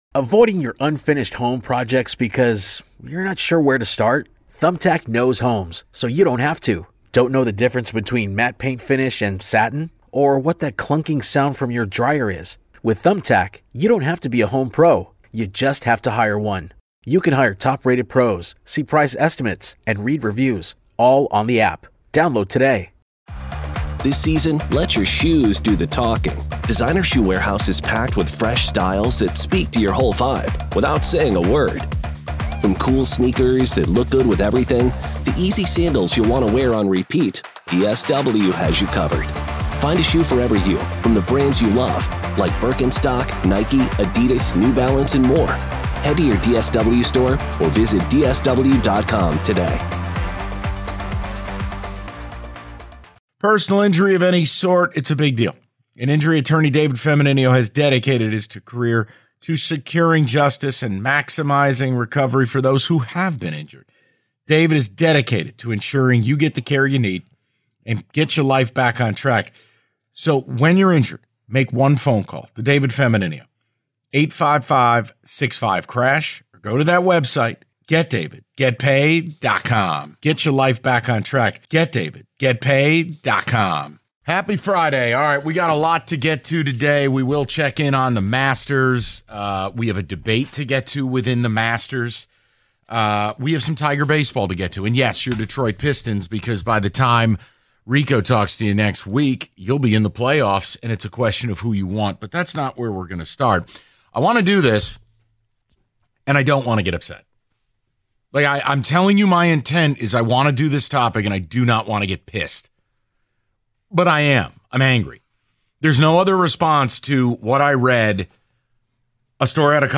They take plenty of your calls and read your ticket texts before doing an "In Football Today" to round out the hour.